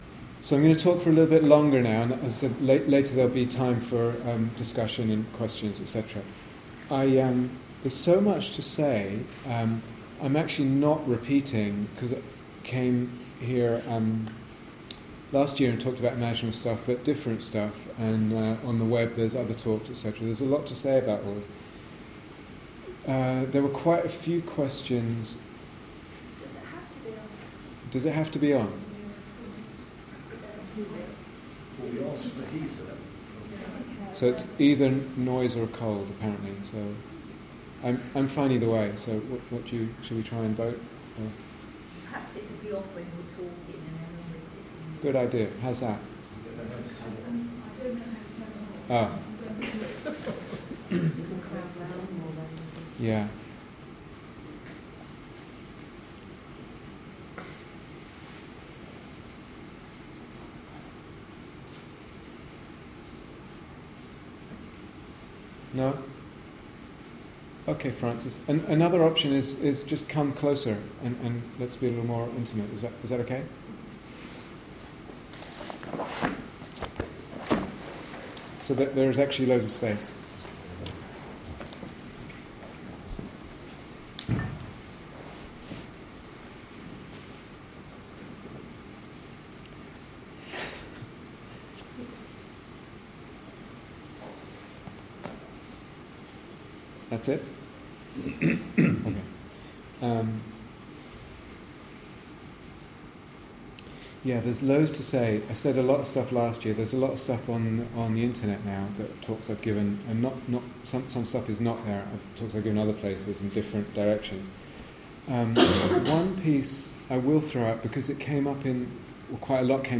Day Retreat, Bodhi Tree Brighton